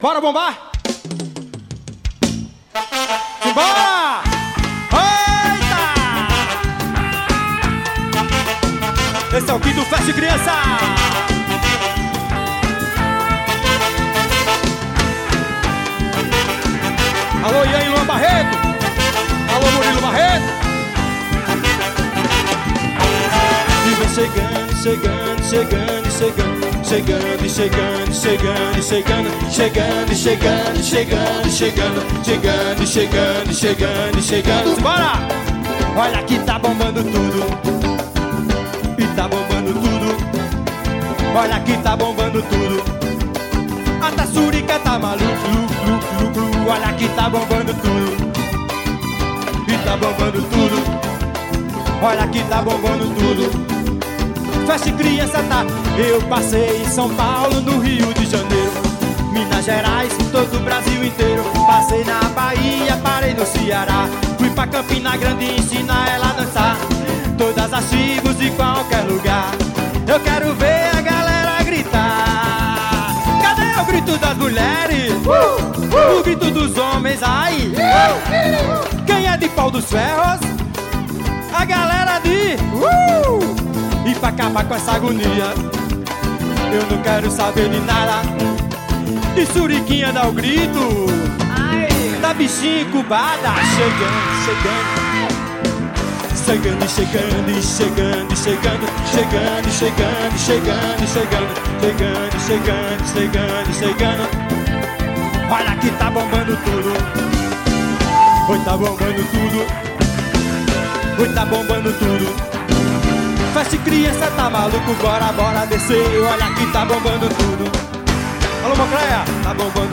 ao vivo fest criança 2009.